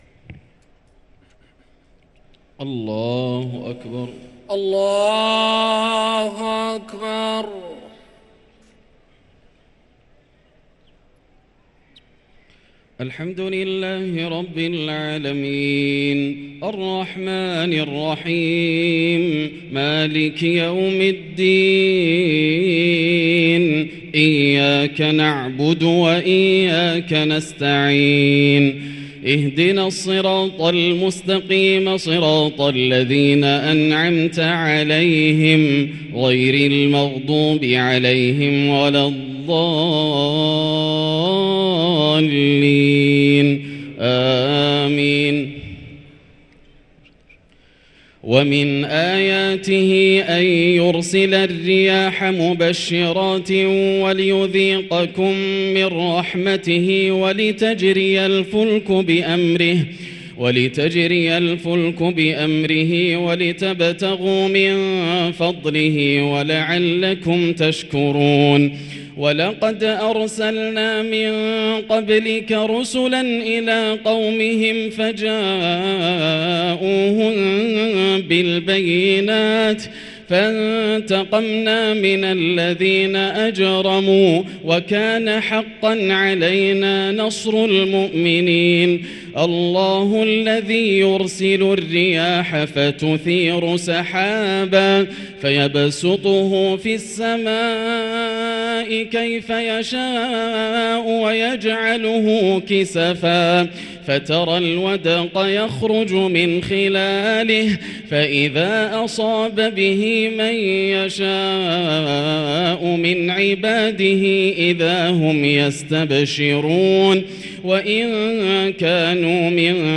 صلاة التراويح ليلة 24 رمضان 1444 للقارئ ياسر الدوسري - التسليمتان الأخيرتان صلاة التراويح
تِلَاوَات الْحَرَمَيْن .